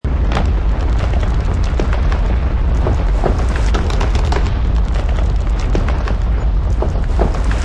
earthquake_cracking_loop.wav